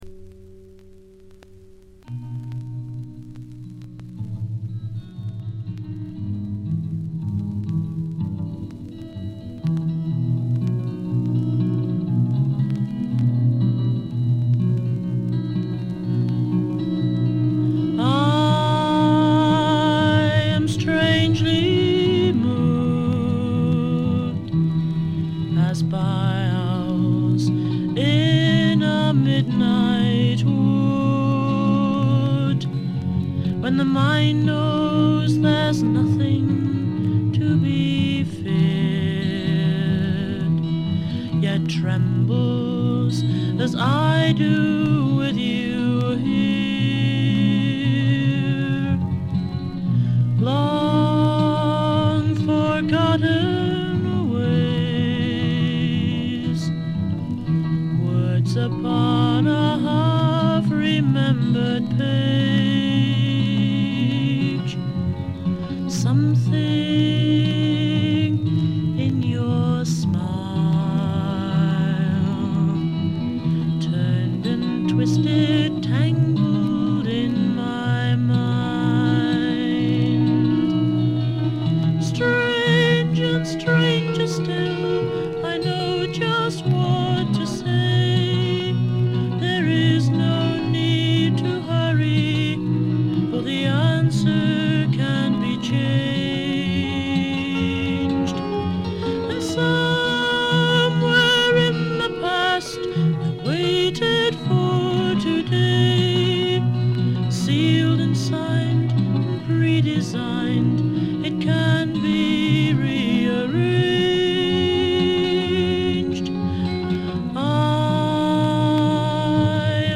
バックグラウンドノイズやや多め大きめですが、鑑賞を妨げるほどのノイズはありません。
試聴曲は現品からの取り込み音源です。